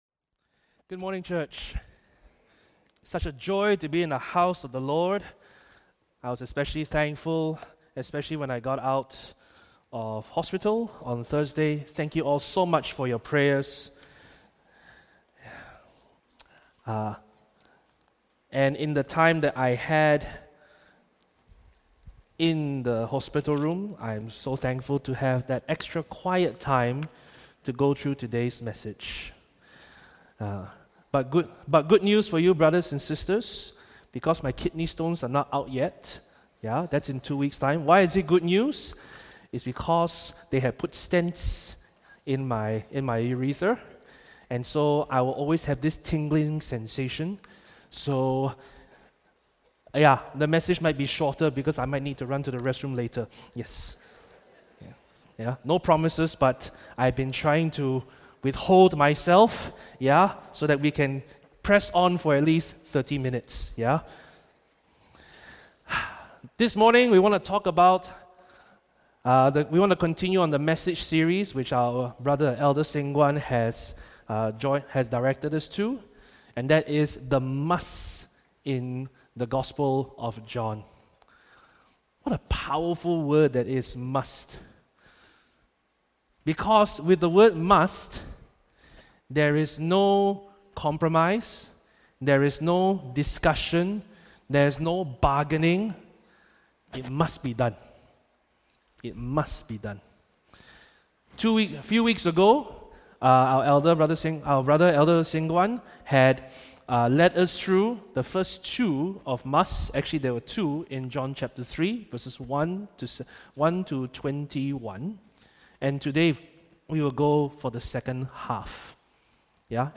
Preacher